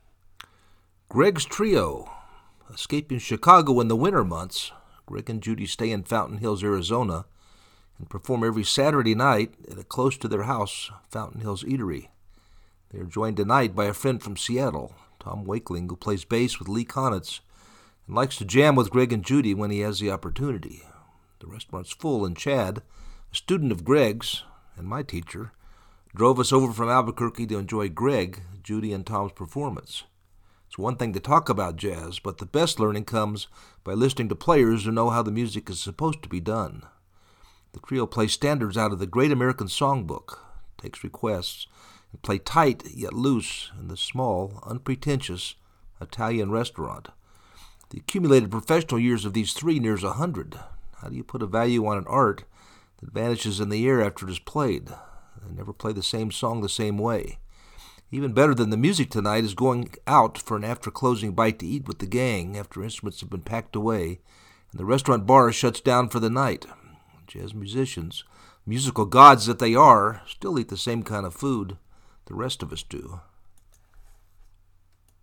bass
It is one thing to talk about jazz, but the best learning comes by listening to players who know how the music is supposed to be done. The trio plays standards out of the Great American Songbook, takes requests, and play tight, yet loose, in this small unpretentious Italian restaurant.